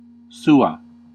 Ääntäminenfor 'sua'
• Classical:
• IPA: /ˈsu.a/